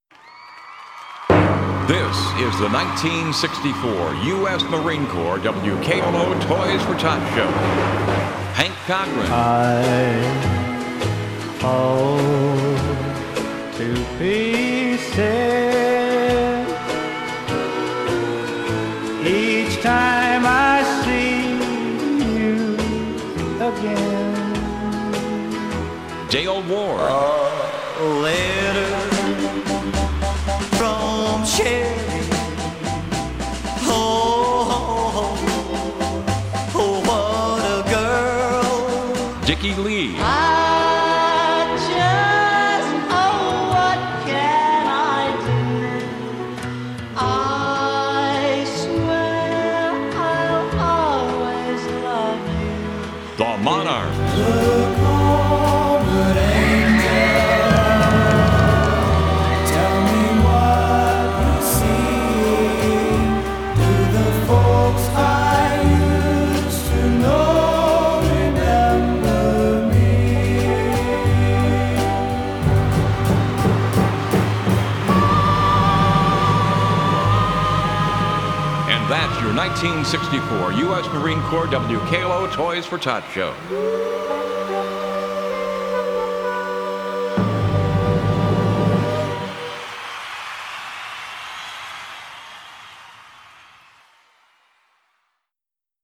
WKLO 1964 Toys for Tots Audio Collage